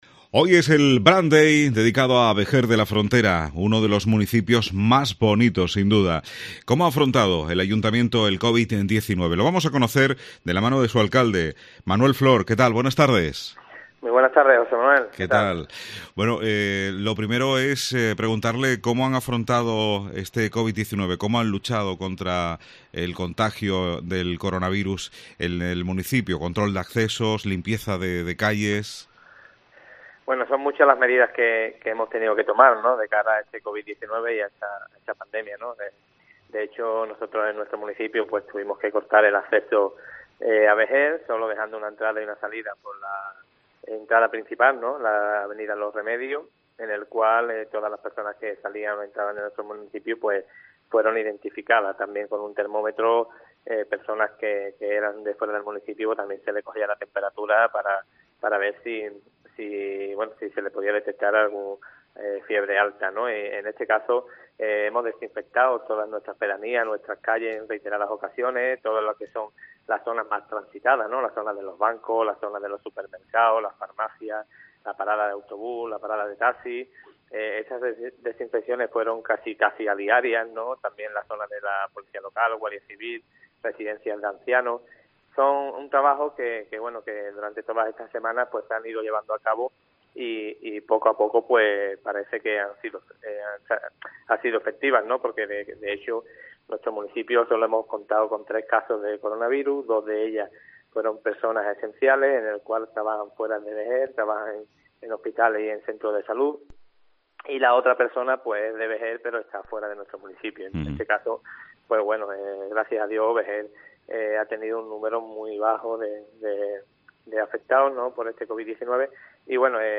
Manuel Flor, alcalde de Vejer, en el Brand Day de COPE